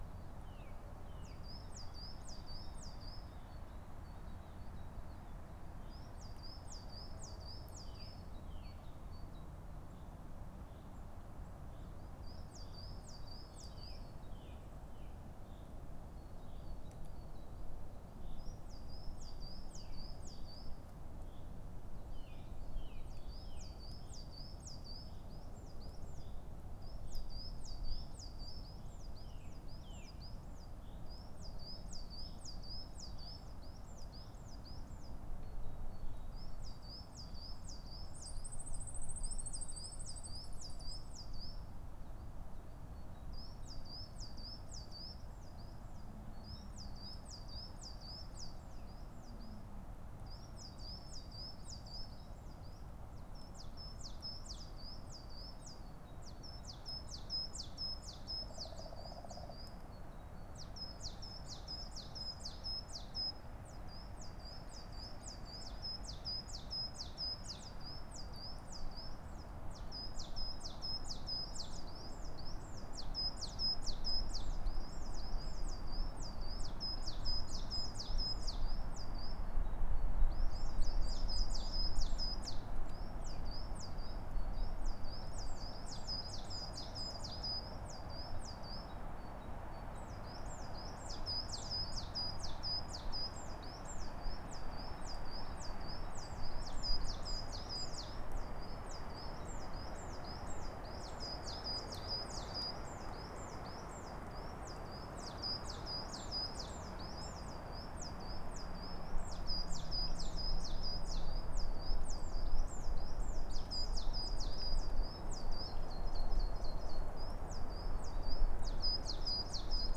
world_assets / audio / ambiance / forestStart.wav
forestStart.wav